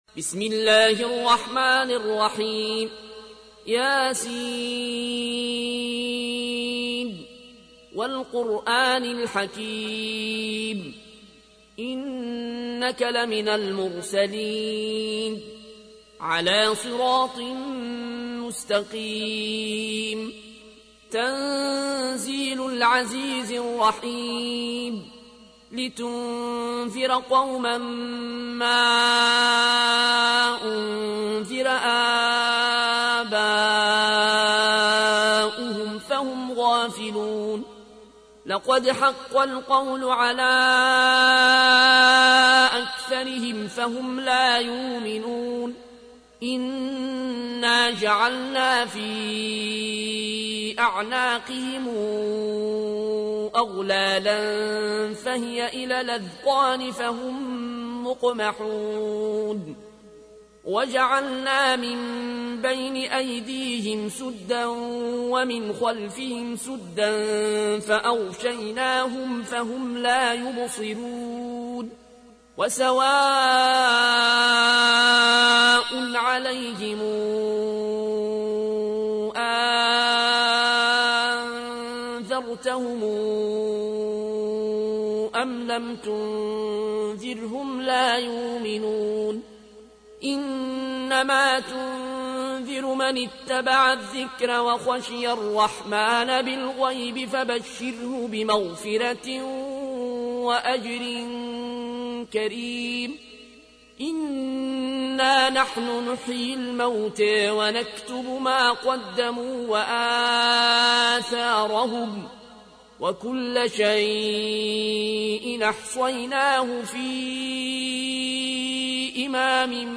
تحميل : 36. سورة يس / القارئ العيون الكوشي / القرآن الكريم / موقع يا حسين